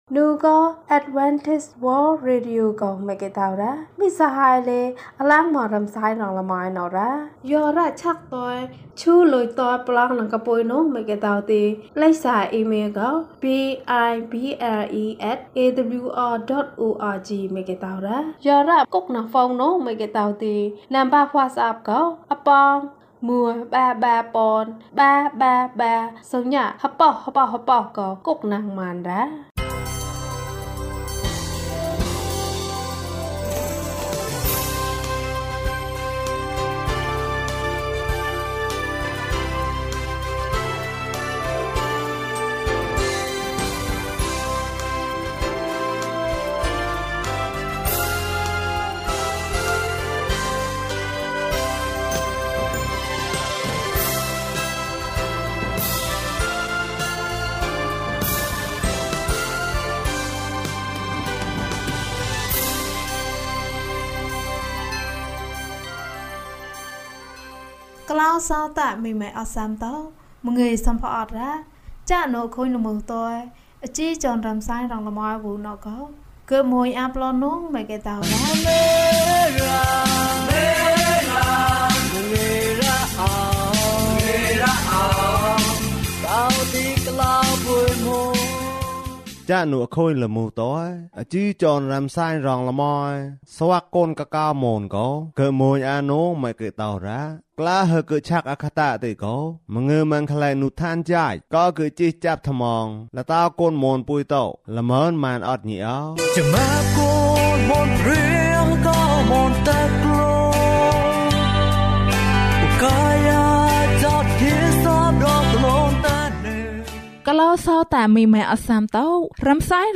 ယေရှုနှင့်အတူအသက်တာ။၀၂ ကျန်းမာခြင်းအကြောင်းအရာ။ ဓမ္မသီချင်း။ တရားဒေသနာ။